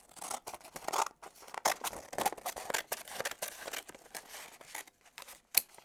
Tijera recortando un papel 3
tijera
Sonidos: Acciones humanas
Sonidos: Oficina